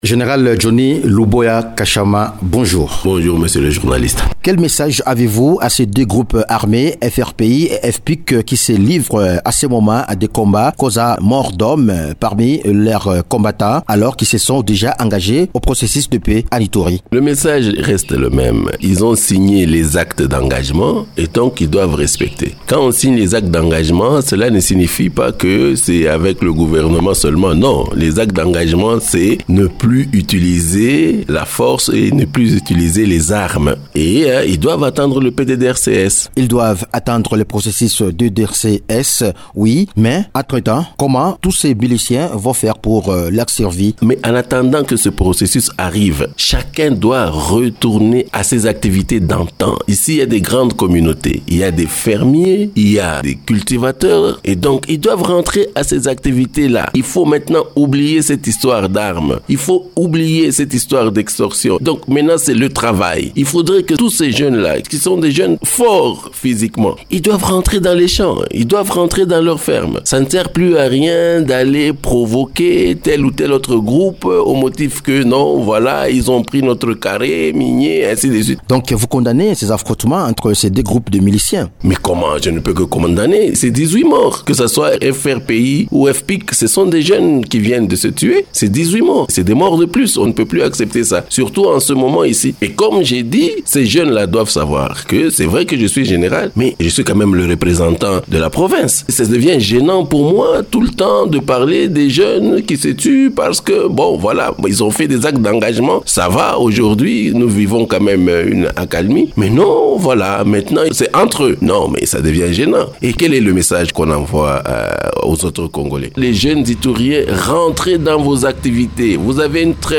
Invité de Radio Okapi, le gouverneur militaire de l’Ituri condamne ces combats et appelle ces miliciens au respect des actes d’engagement de cessation des hostilités dans cette contrée.